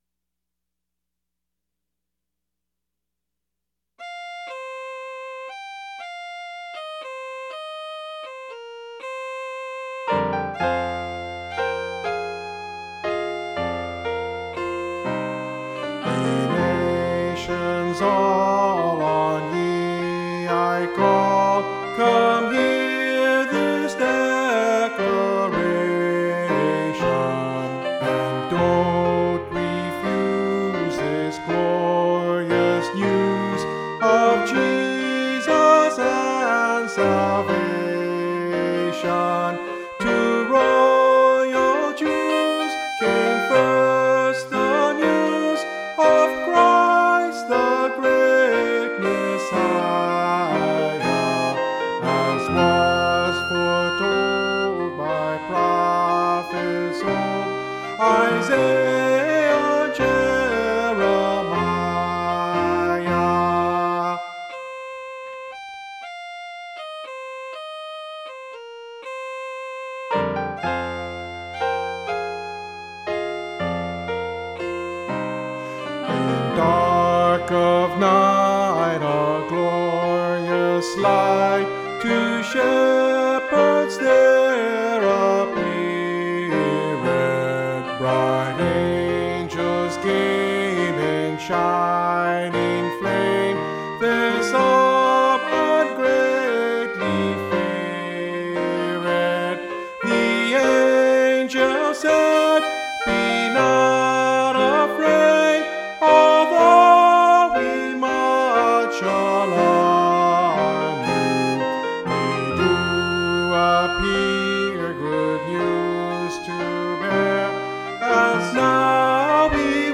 Mountain Christmas Carol
Soprano 1   Instrumental | Downloadable